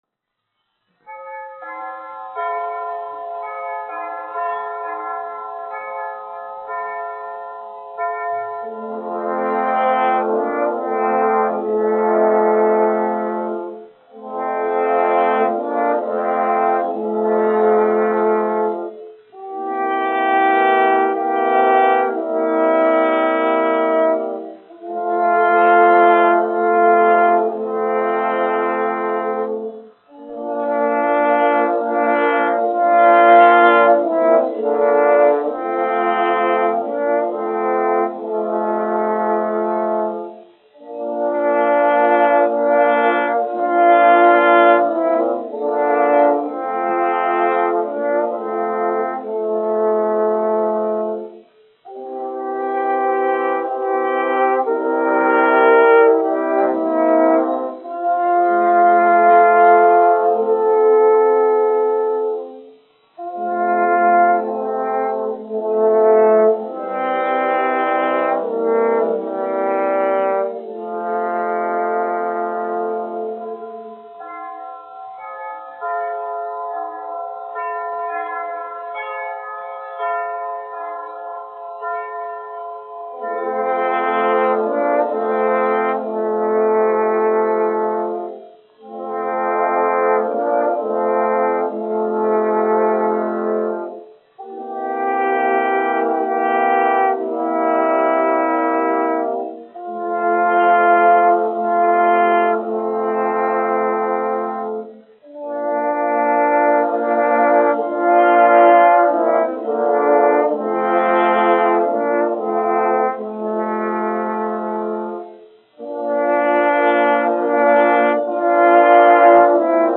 1 skpl. : analogs, 78 apgr/min, mono ; 25 cm
Ziemassvētku mūzika
Metāla pūšaminstrumentu kvarteti (tromboni (4))
Skaņuplate